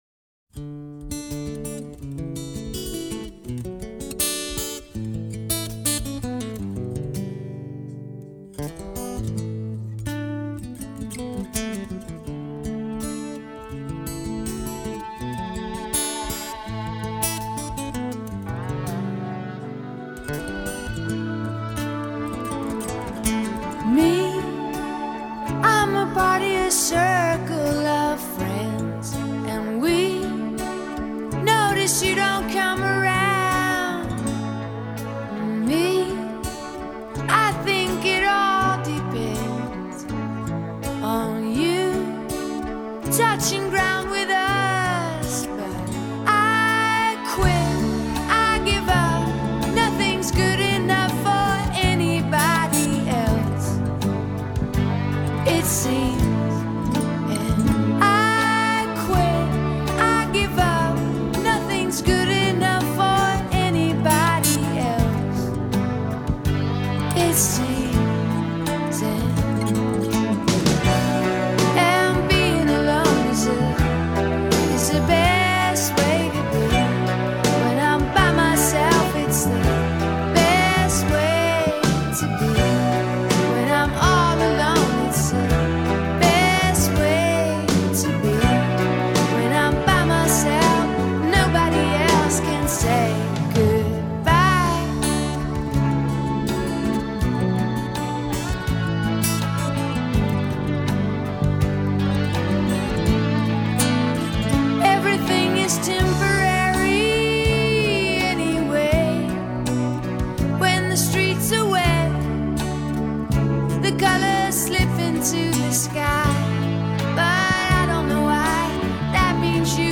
singular lead singer